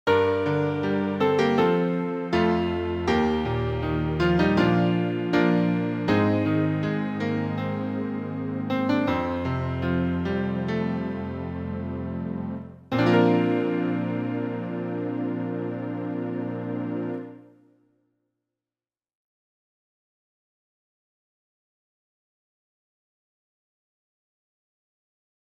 ジングル系